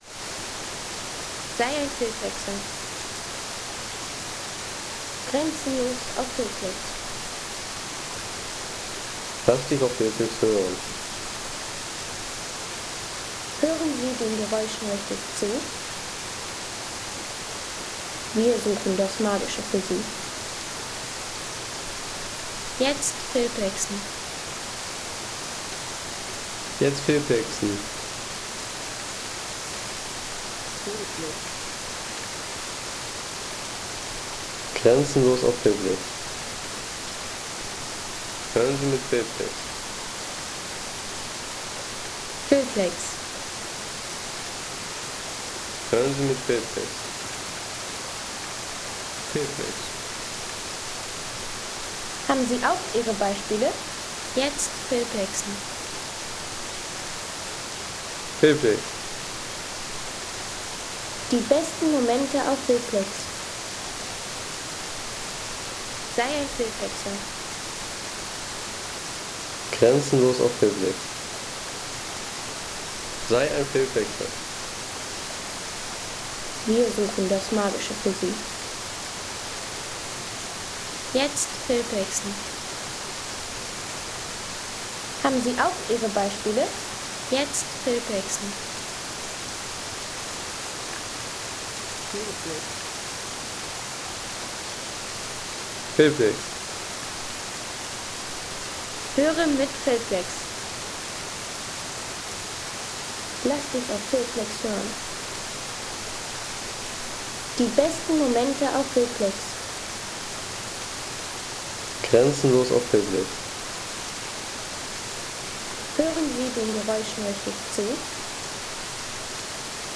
Peaceful water sounds from the Veilfall in Gesäuse National Park – dripping curtain, mossy ground, and pure natural ambience.
Water Murmur in the Mossy Trail of Gesäuse
Delicate water sounds from the Veilfall in Gesäuse National Park – a curtain of droplets, moss-covered ground, and nature’s quiet presence.